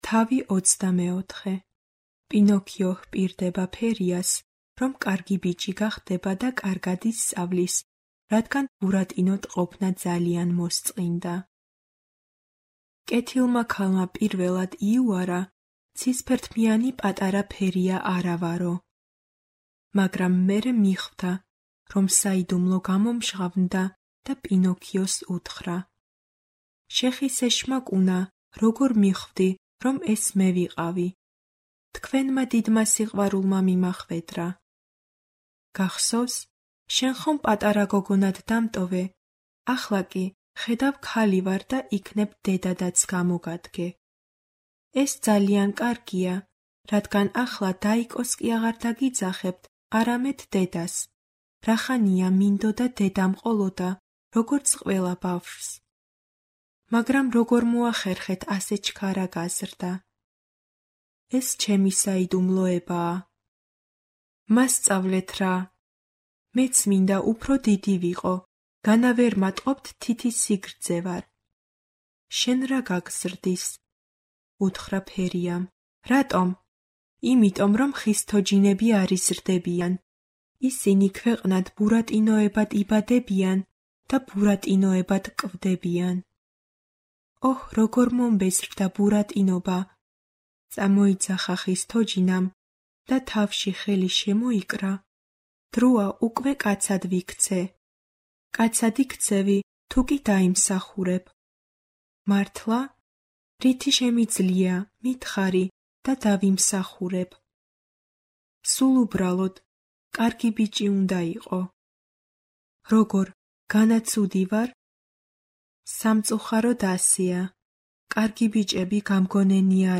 შესულია კოლექციებში:ეროვნული ბიბლიოთეკის აუდიო წიგნები